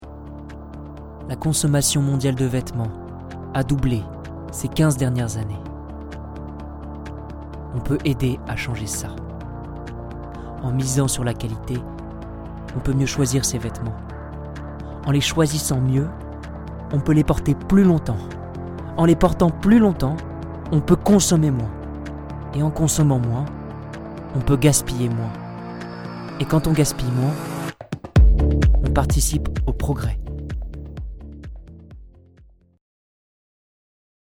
Voix off
11 - 31 ans - Ténor